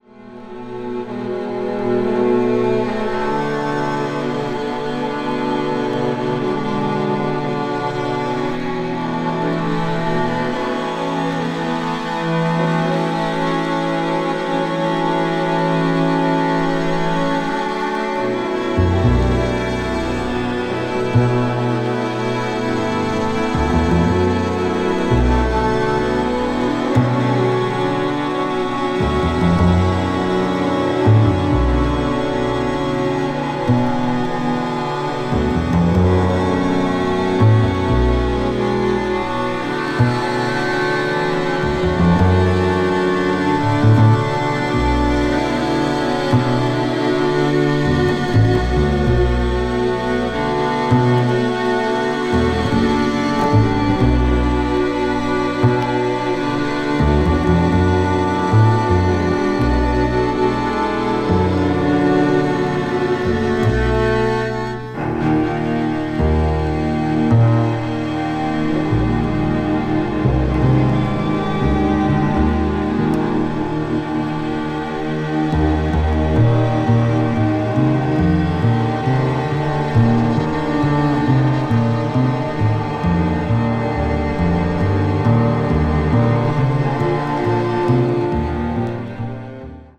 a deep soundscape with cello and bass alone